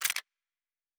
pgs/Assets/Audio/Sci-Fi Sounds/Weapons/Weapon 09 Foley 3.wav
Weapon 09 Foley 3.wav